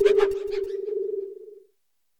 Cri de Bérasca dans Pokémon Écarlate et Violet.